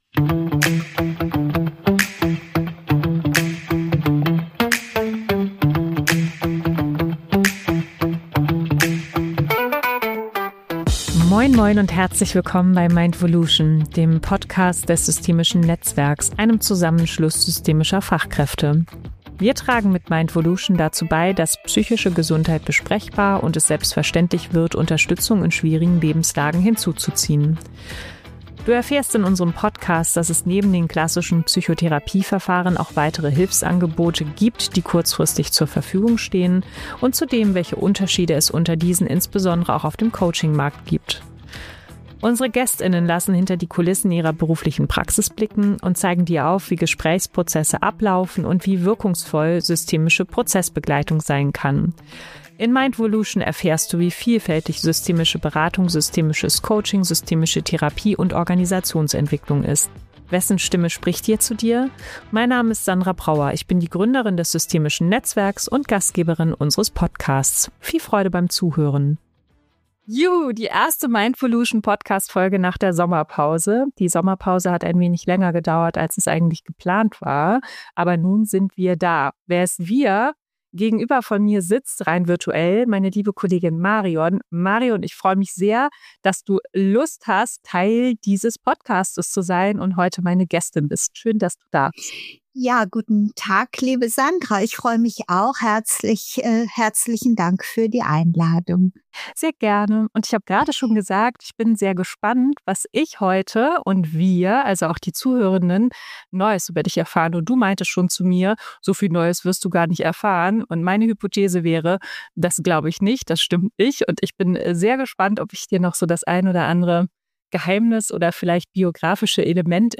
Die beiden tauchen tief in die Welt der systemischen Beratung, Therapie und persönlichen Entwicklung ein. Sie sprechen über biografische Wendepunkte, die Kraft von Krisen und was es bedeutet, mit Herz, Erfahrung und systemischer Haltung zu arbeiten.